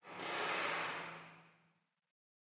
ambienturban_19.ogg